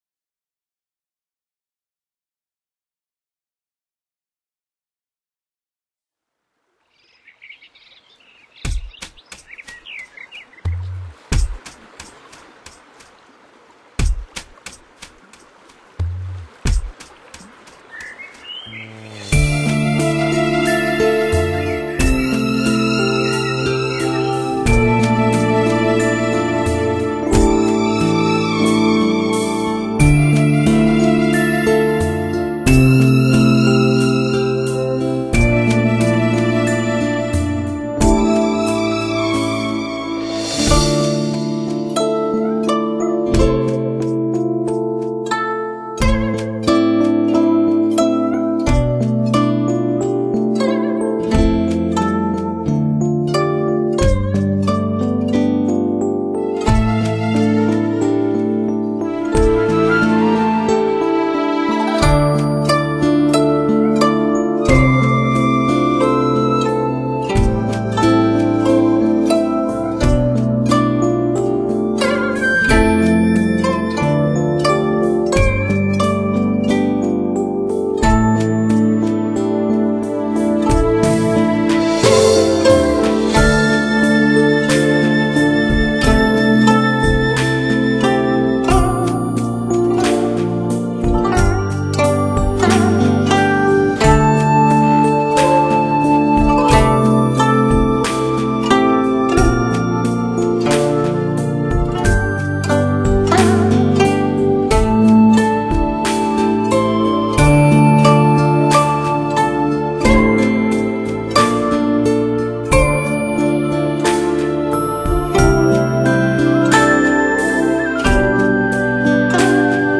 专辑类型：DTS-CD